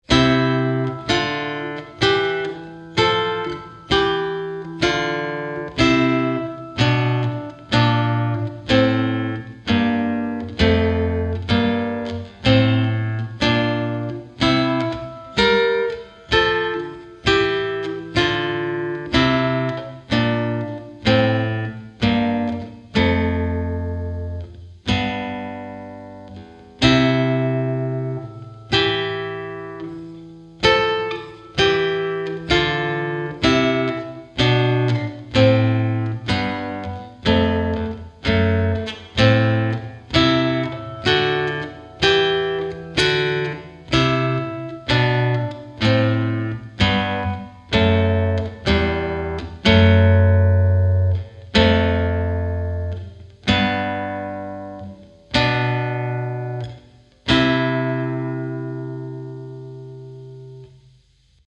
Chitarra sola 52